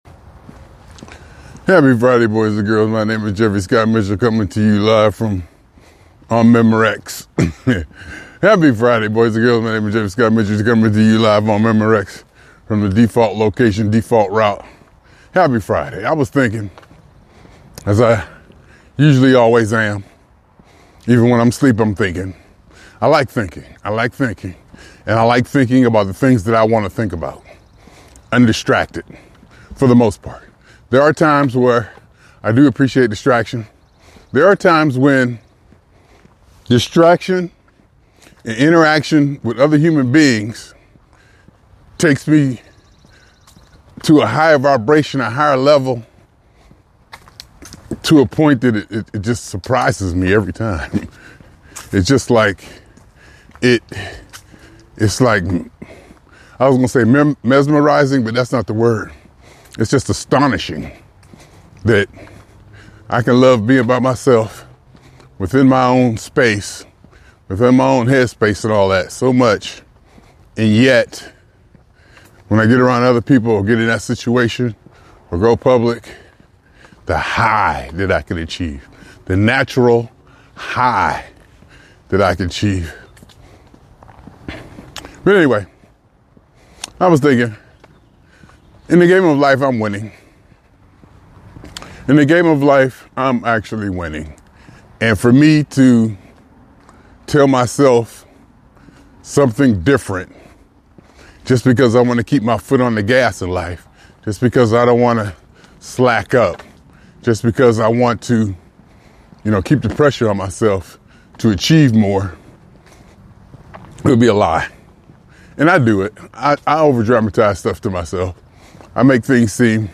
In a reflective talk